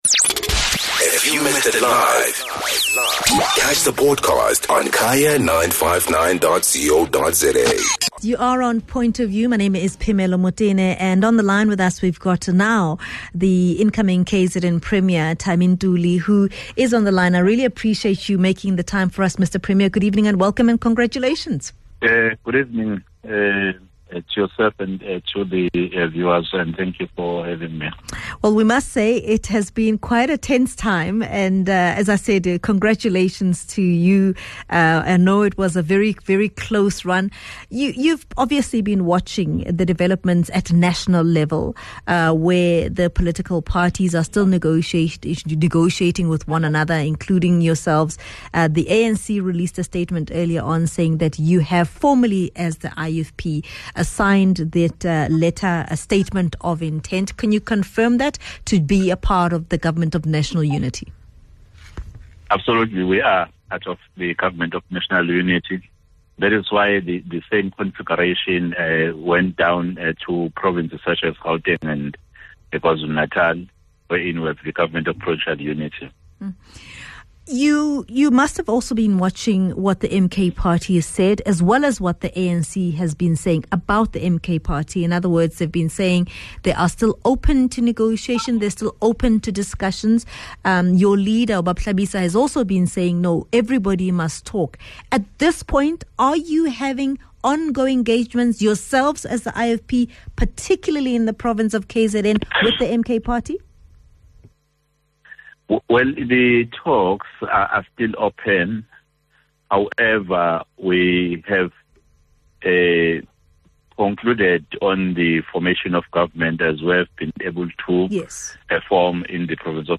Guest: Thami Ntuli, newly elected KZN Premier